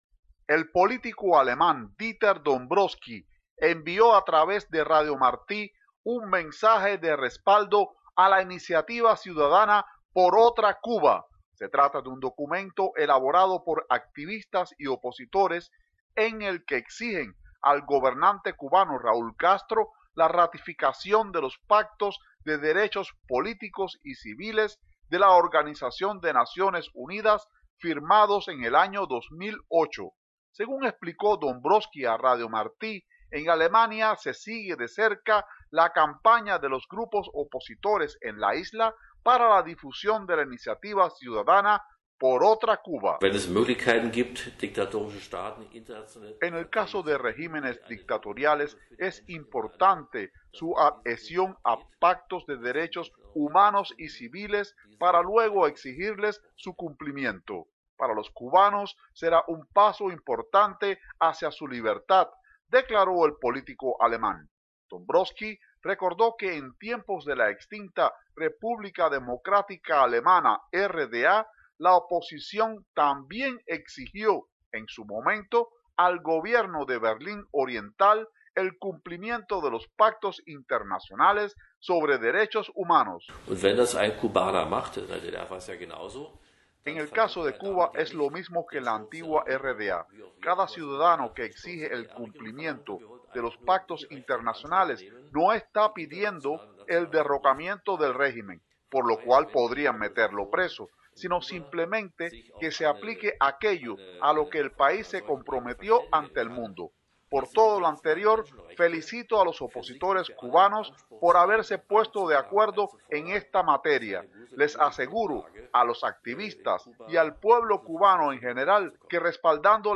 Dieter Dombrowski, de la Unión Cristianodemócrata de Alemania conversó con Martí Noticias